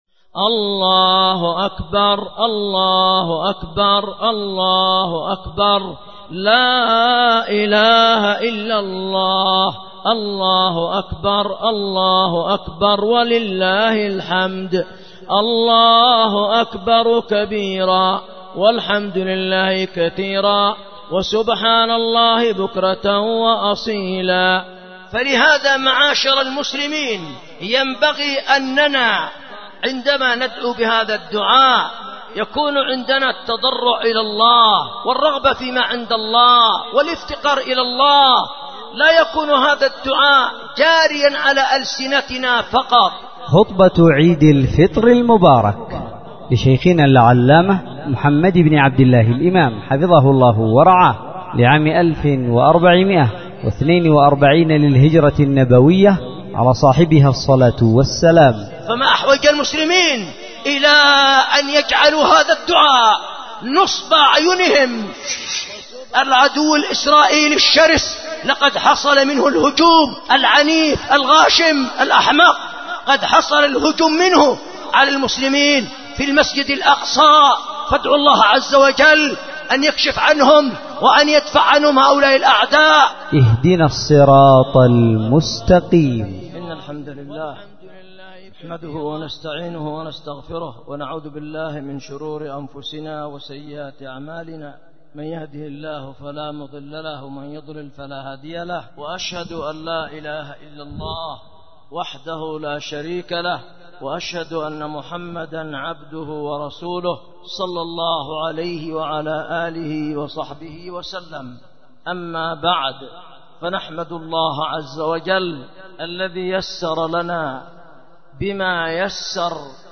خطبة عيد الفطر 1442هجرية